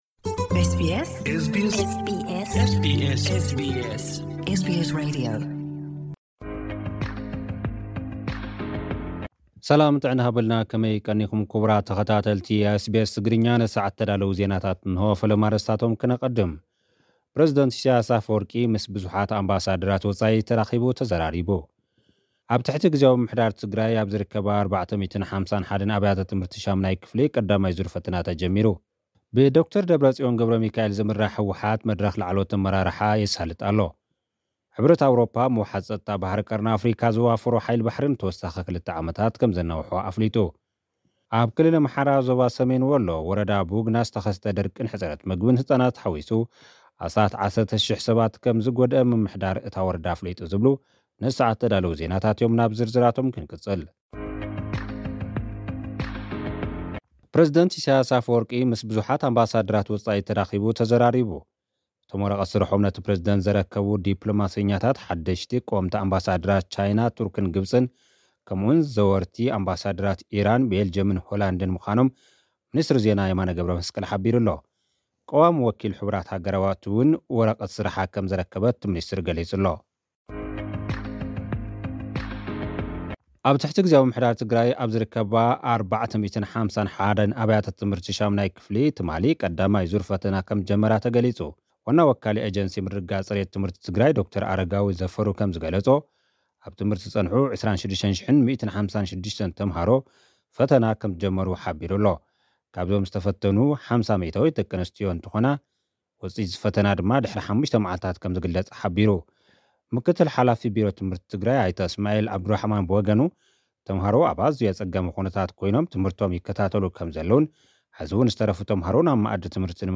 ጸብጻባት ዜና